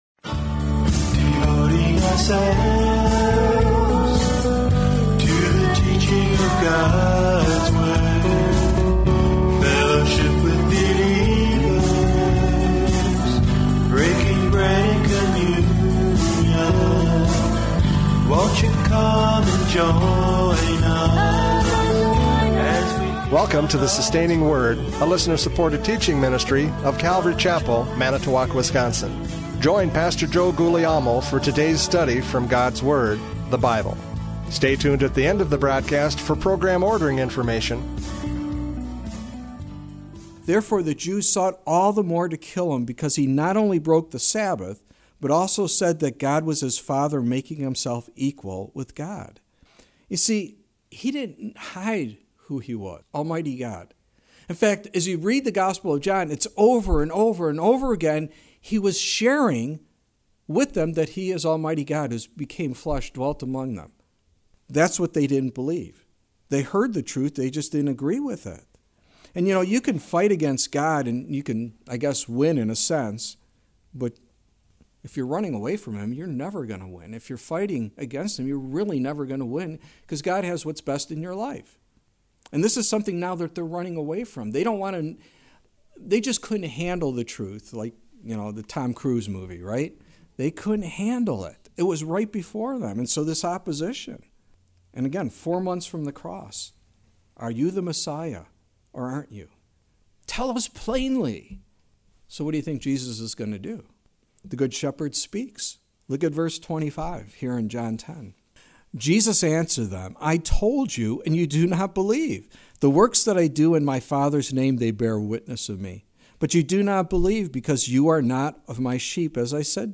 John 10:22-42 Service Type: Radio Programs « John 10:22-42 The Good Shepherd Speaks!